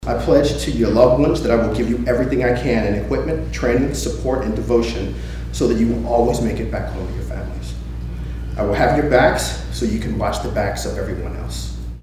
In his remarks, the new department head committed himself to be accountable and supportive to the community and those working in his new department.